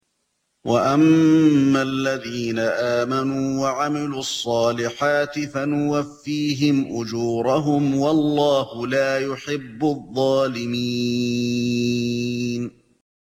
2- ورش
استمع للشيخ الحذيفي من هنا